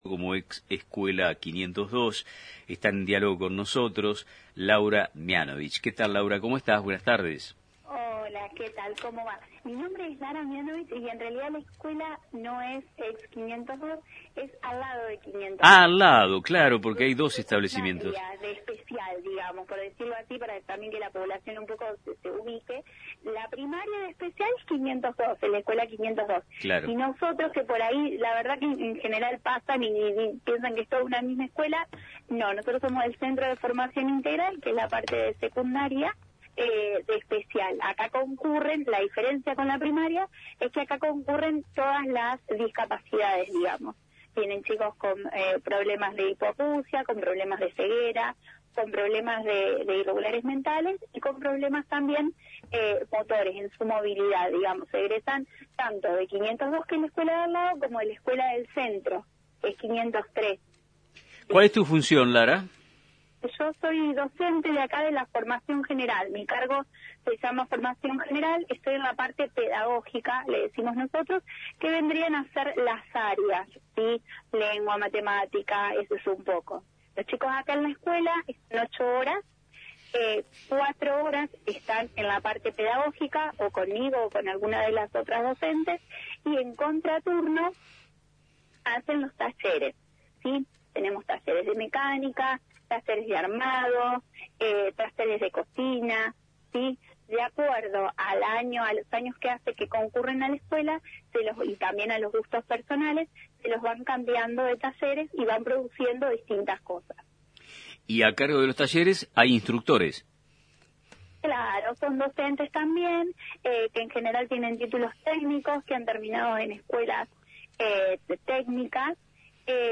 En diálogo con el programa Nuestro Tiempo